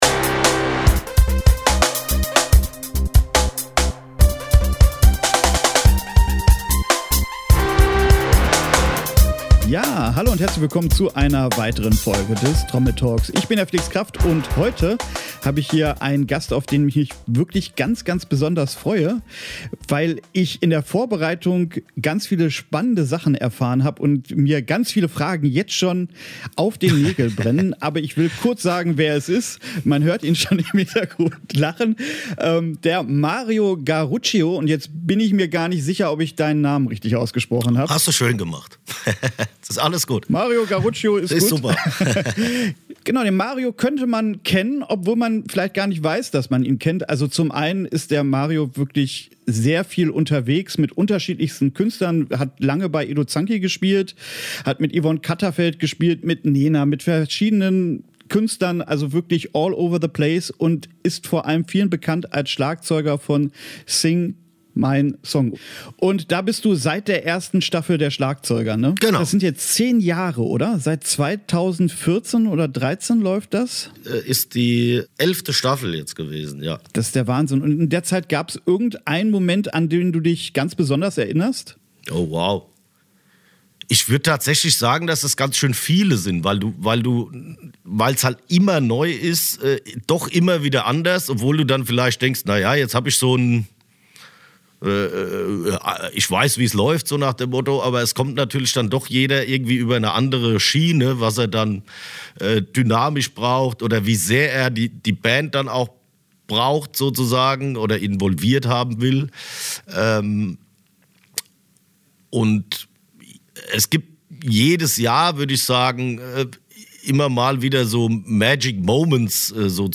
Kapitelmarken - mit hehren Zielen und einem Zettel in der Hand, bin ich das Interview angegangen.